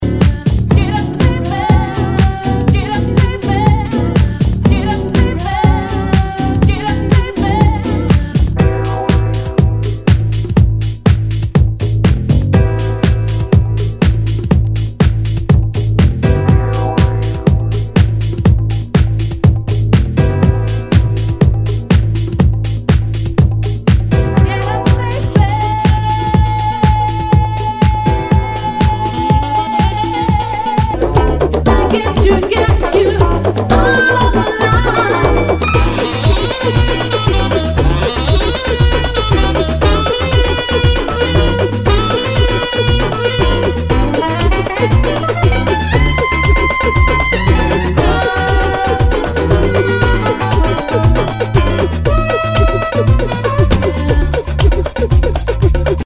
HOUSE/TECHNO/ELECTRO
ナイス！ヴォーカル・ハウス！